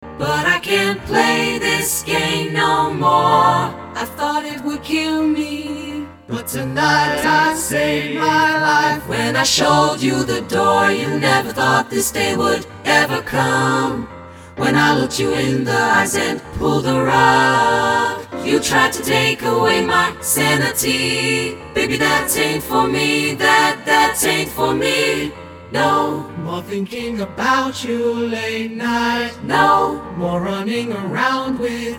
This accessible SSATB arrangement